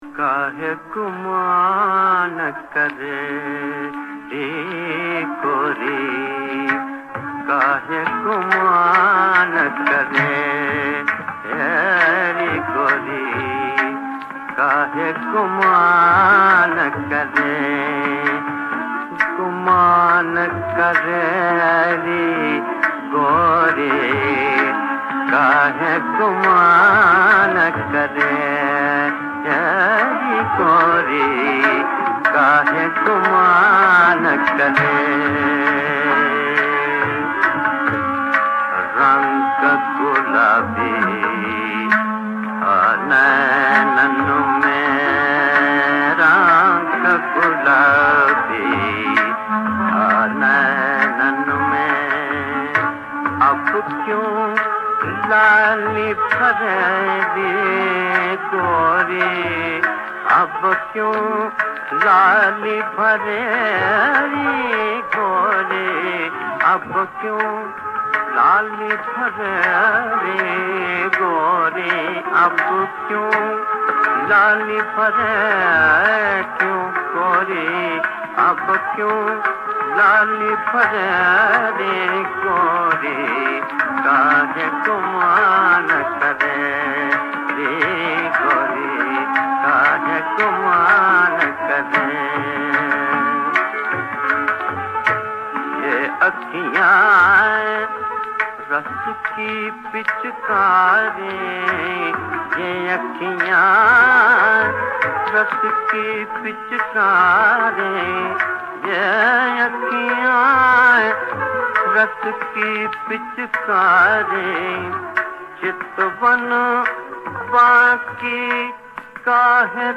Raga Piloo